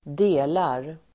Uttal: [²d'e:lar]